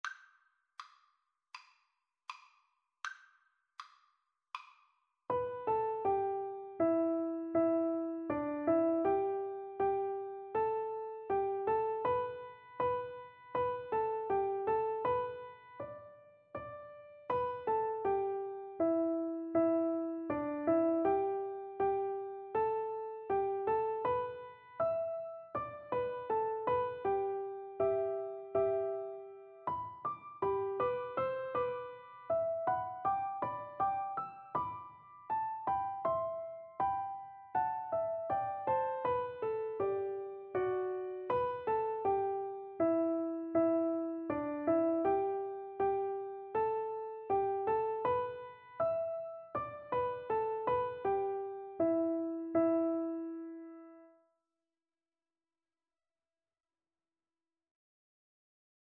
4/4 (View more 4/4 Music)
Andante Espressivo = c. 80